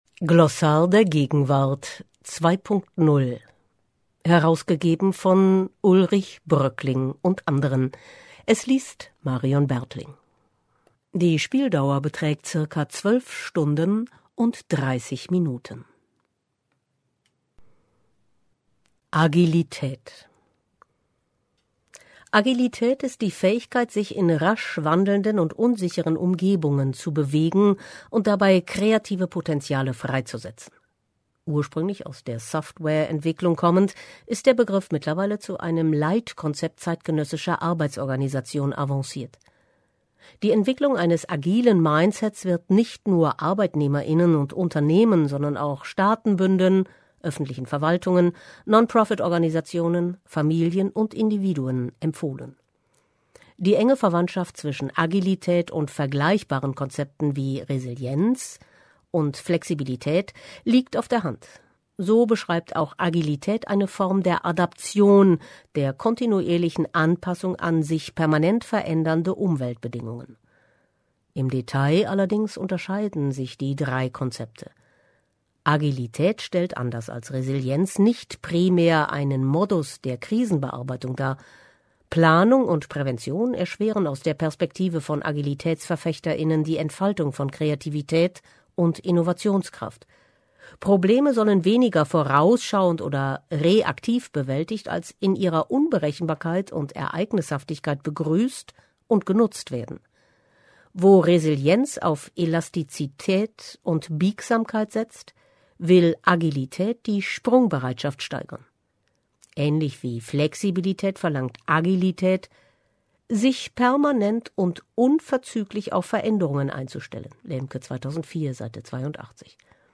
Buchtipps - 03. Neues im März